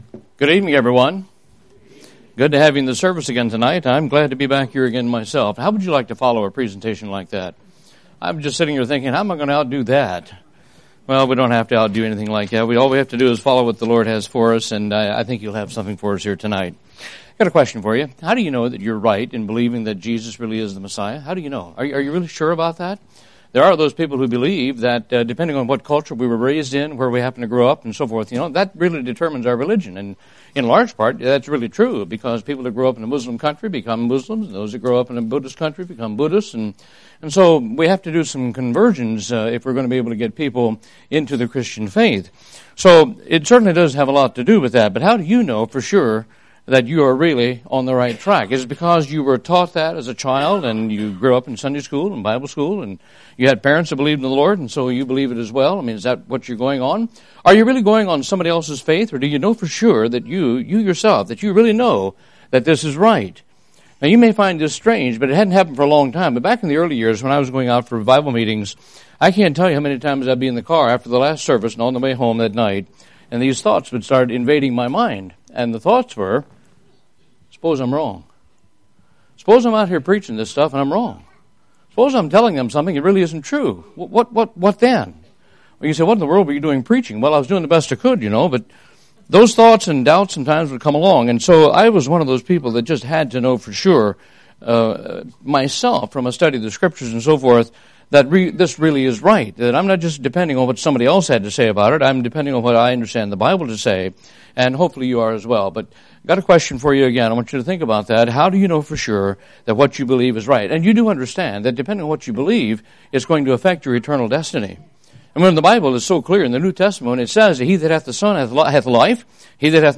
Download Sermons Previous Post Are We Going to Escape the Tribulation?